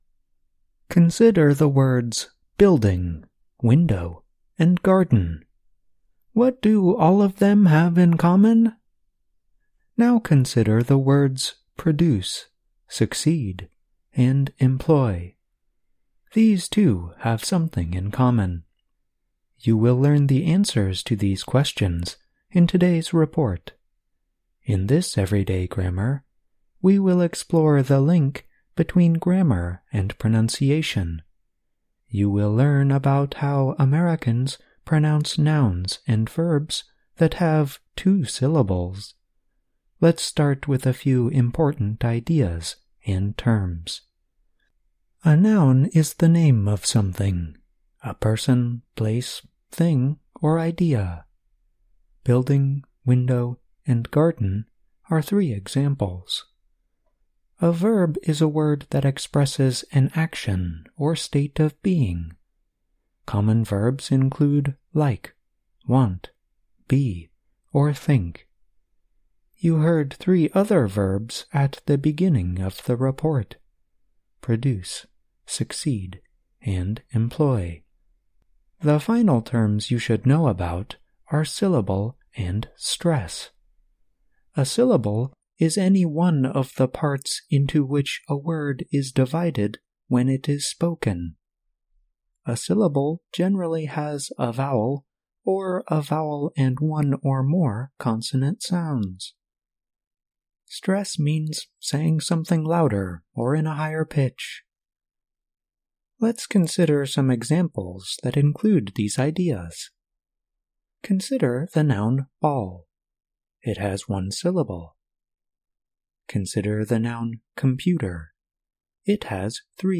In this week's Everyday Grammar, you will learn about the link between grammar and pronunciation in American English. You will learn how Americans use syllable stress in regular ways when pronouncing nouns and verbs.